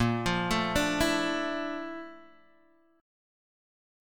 A#M#11 chord